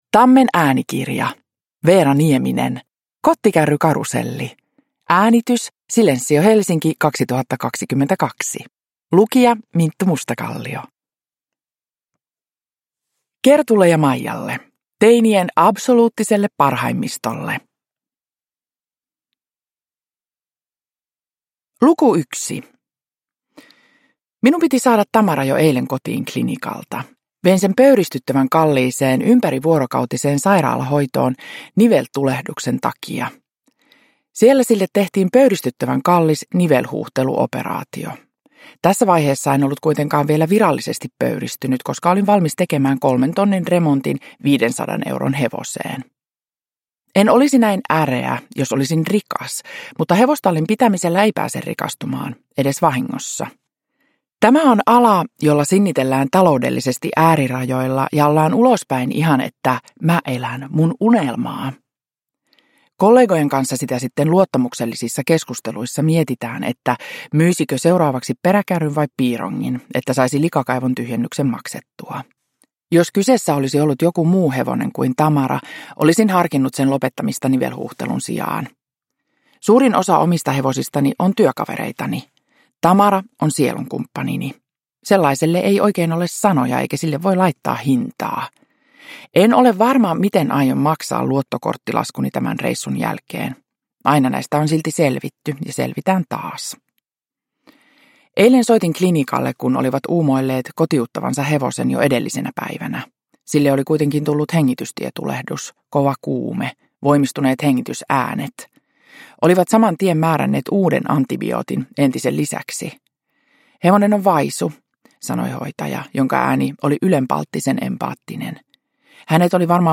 Kottikärrykaruselli – Ljudbok
Uppläsare: Minttu Mustakallio